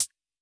Index of /musicradar/retro-drum-machine-samples/Drums Hits/WEM Copicat
RDM_Copicat_MT40-ClHat.wav